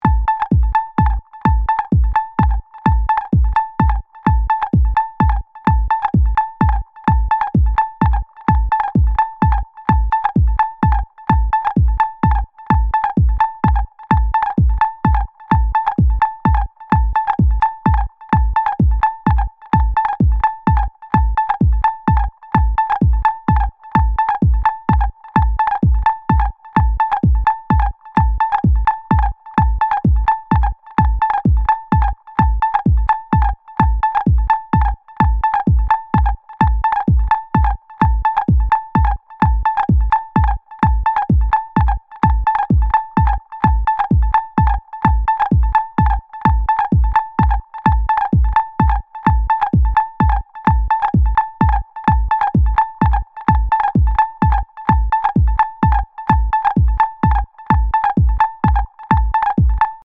is inspired by trance and especially psytrance